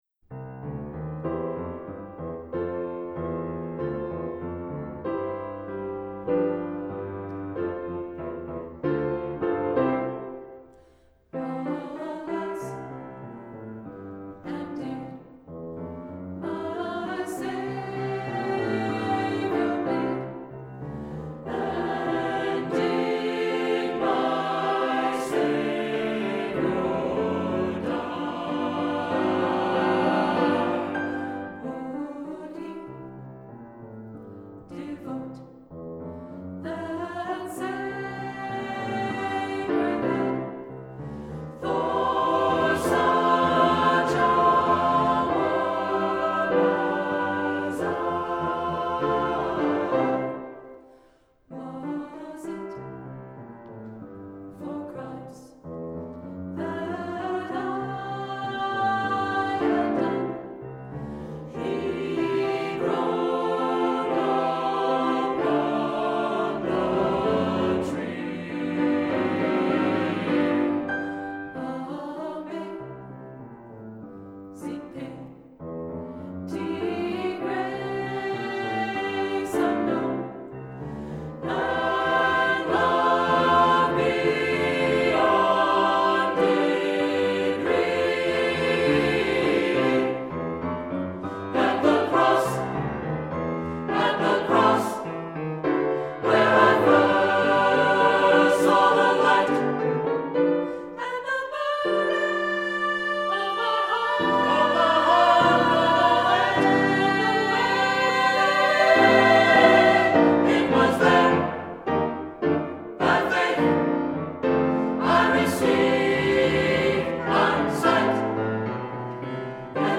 Accompaniment:      With Piano
Music Category:      Christian
" this setting is a first-rate gospel funk setting.